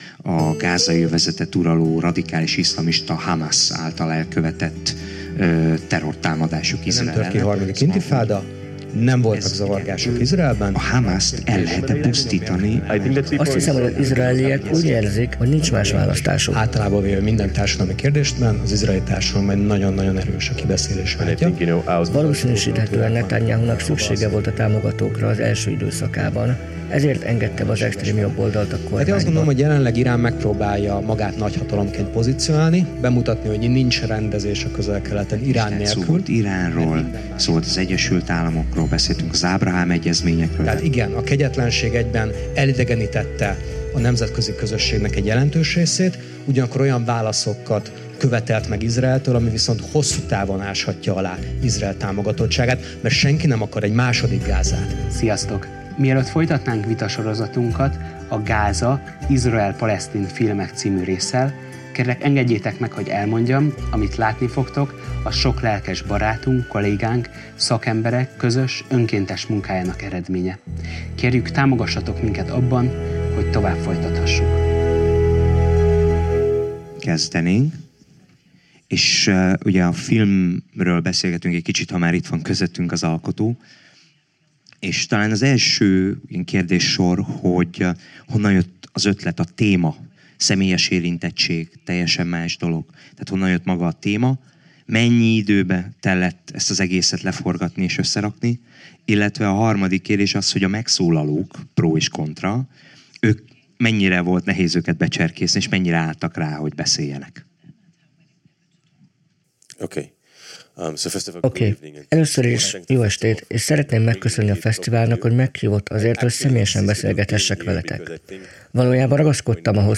amelyet a 2024-es BIDF-en rögzítettünk